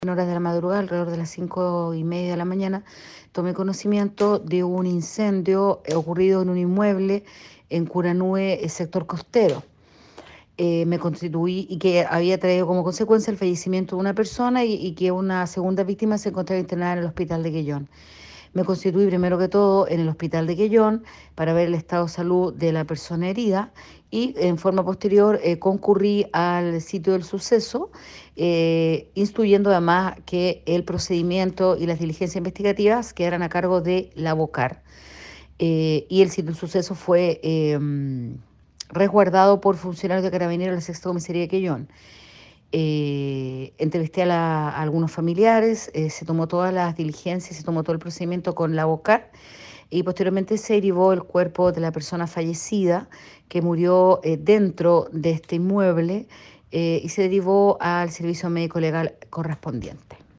En tanto, desde la fiscalía de Quellón se corroboró que la investigación está a cargo de Labocar de Carabineros, como lo expresó la fiscal Karyn Alegría.